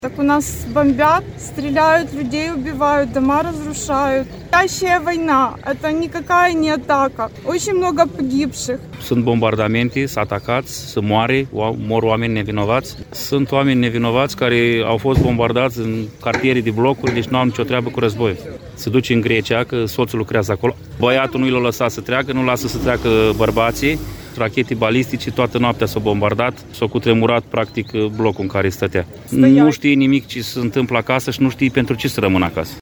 O altă ucraineancă